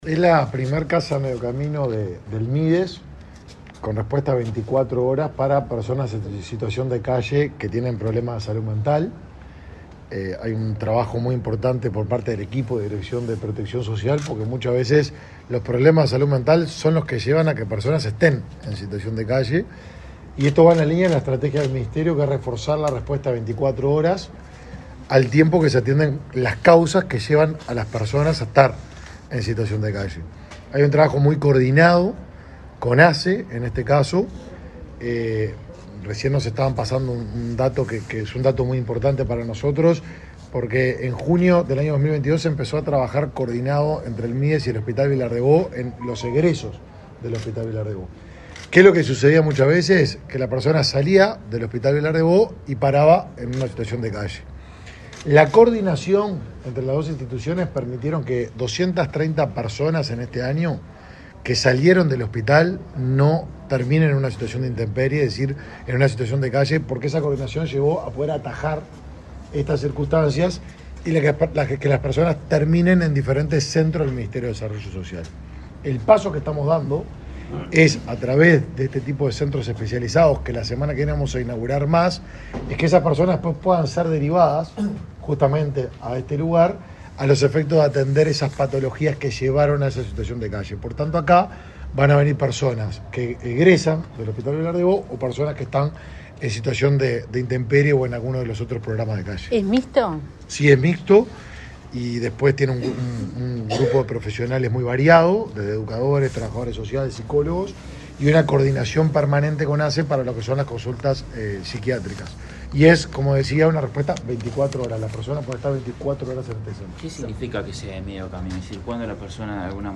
Declaraciones del ministro de Desarrollo Social, Martín Lema
Declaraciones del ministro de Desarrollo Social, Martín Lema 21/07/2023 Compartir Facebook X Copiar enlace WhatsApp LinkedIn El ministro de Desarrollo Social, Martín Lema, dialogó con la prensa luego de inaugurar en Montevideo una casa de medio camino para personas con problemas de salud mental.